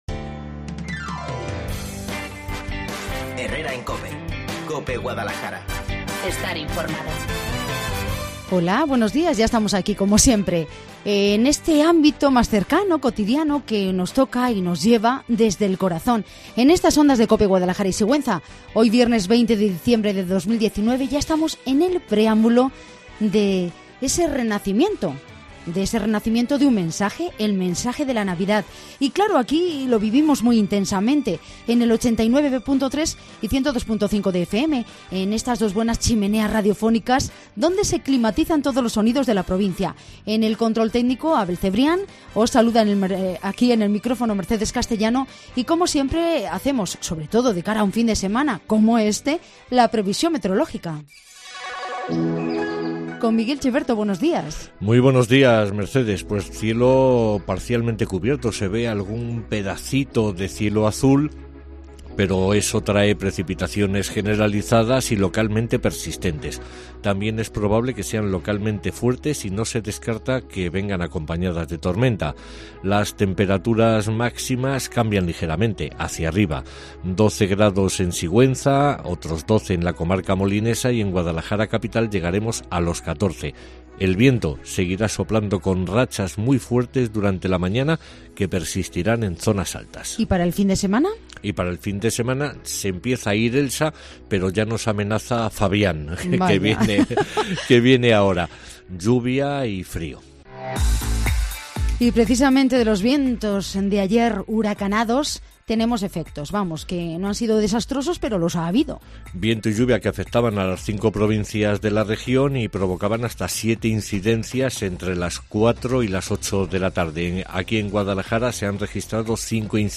Pregón de Navidad